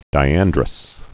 (dī-ăndrəs)